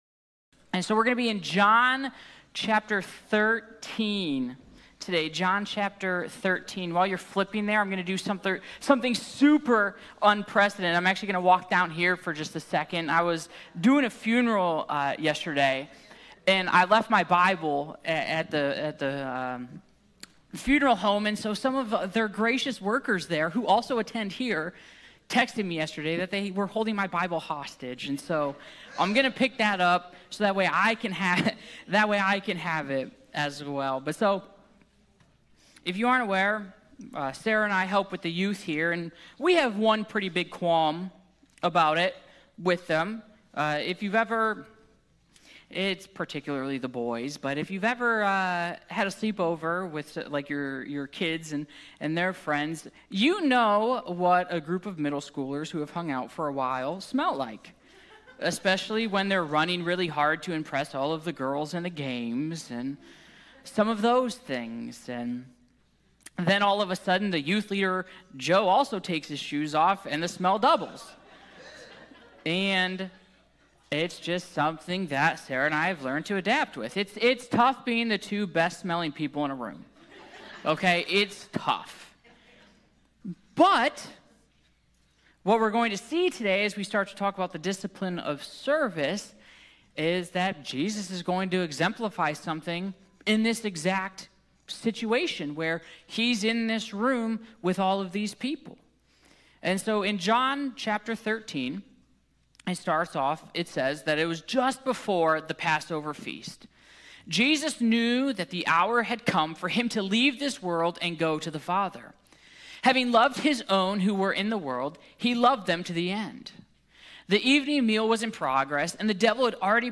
True greatness in God’s kingdom begins with serving others. This sermon unpacks the spiritual discipline of service—why it matters, how it transforms us, and how we can follow Christ’s example of humble love in our daily lives.